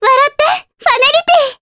Ophelia-Vox_Skill6_jp.wav